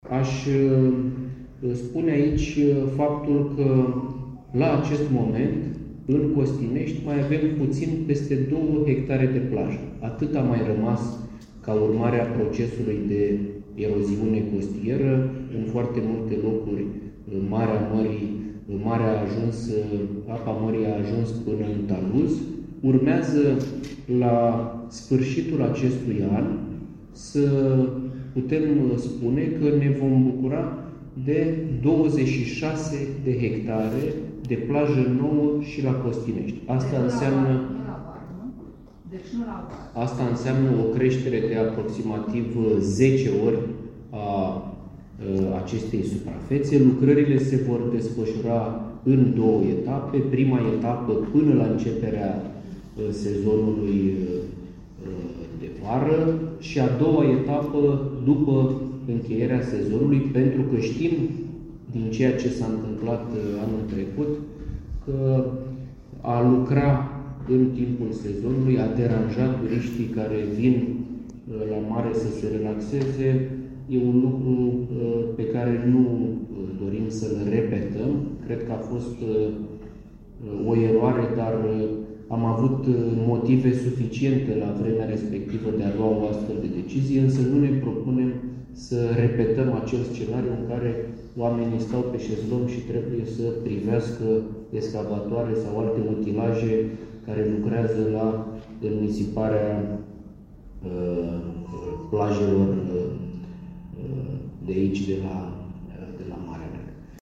Într-o conferință de presă, ministrul Mediului, Mircea Fechet, a declarat că lucrările de construire a digurilor au început mai devreme decât era prevăzut, finalizarea construcțiilor hidrotehnice fiind estimată înainte de debutul sezonului estival, iar înnisiparea va avea loc în toamnă, pentru a nu-i deranja pe turiști: